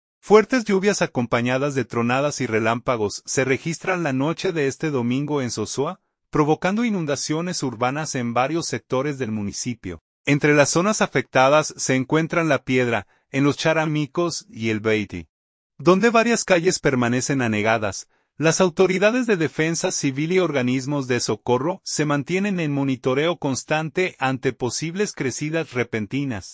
Fuertes lluvias acompañadas de tronadas y relámpagos se registran la noche de este domingo en Sosúa, provocando inundaciones urbanas en varios sectores del municipio.